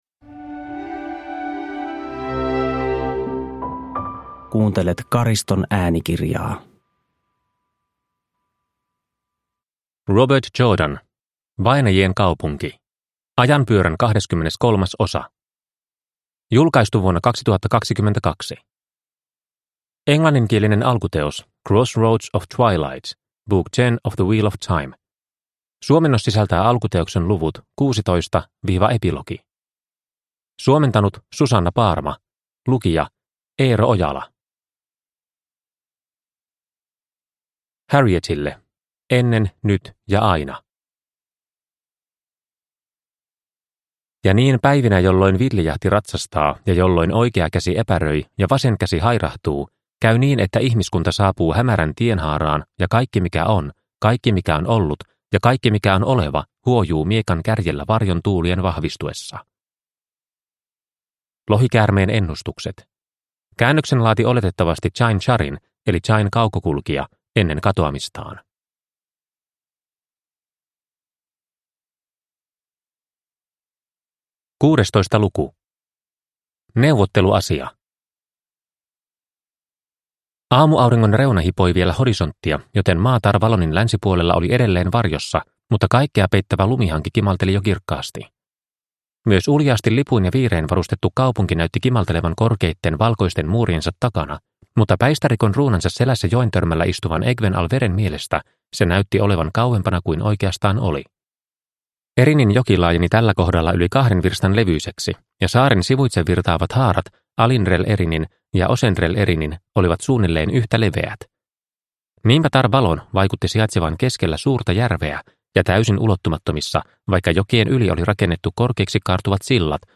Vainajien kaupunki – Ljudbok – Laddas ner